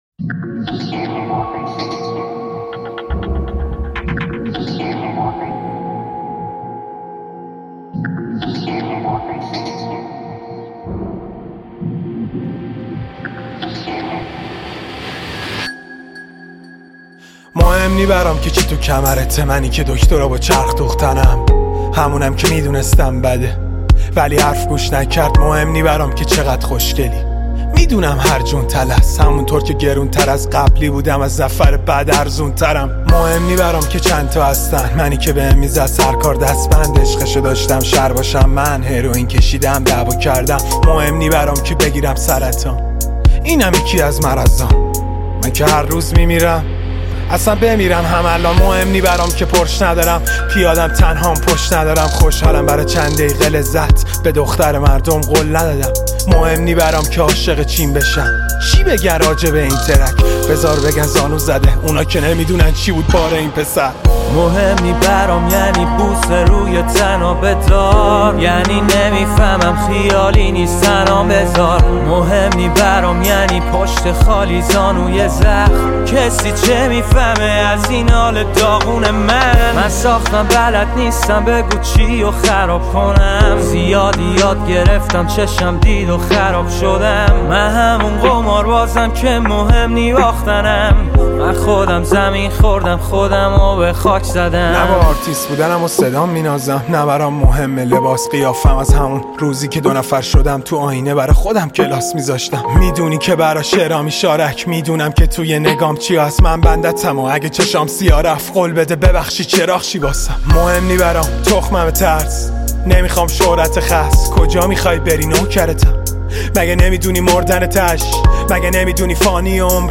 گنگ رپ